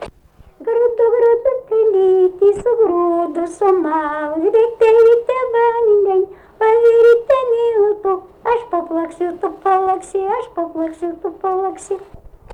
smulkieji žanrai
Bagdoniškis
vokalinis